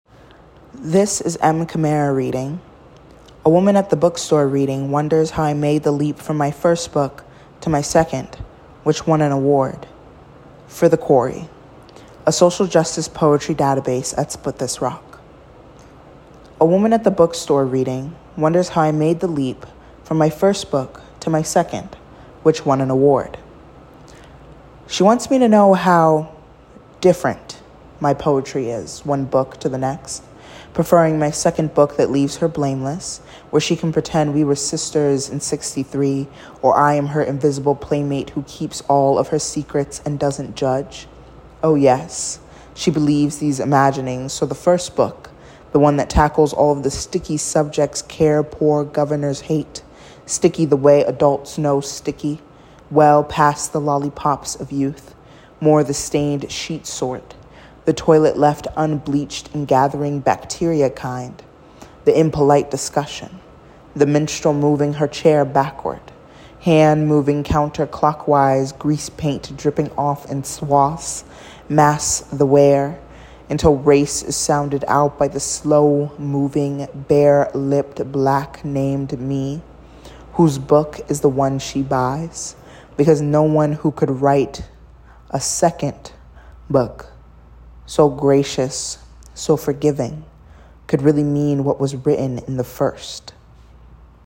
Listen to a reading of A WOMAN AT THE BOOKSTORE READING WONDERS HOW I MADE THE LEAP FROM MY FIRST BOOK TO MY SECOND WHICH WON AN AWARD